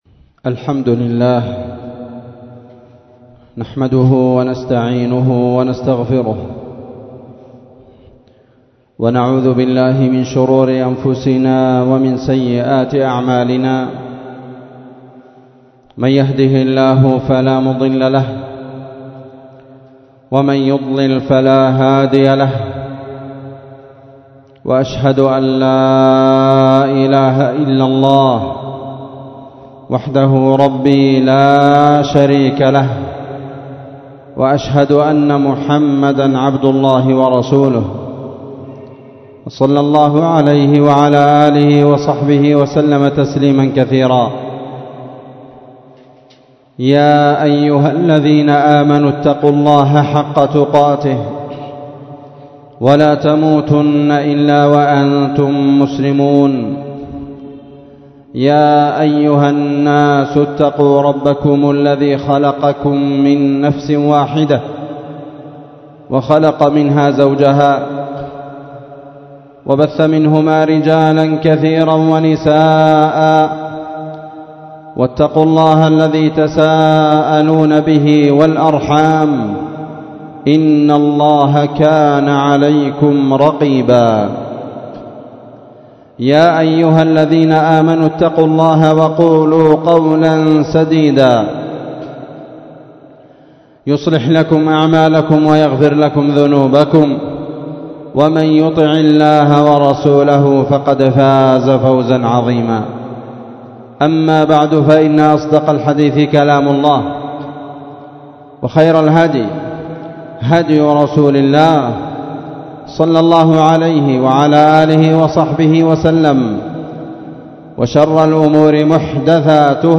خطبة جمعة بعنوان *لفت النظرات إلى أنواع الحسرات*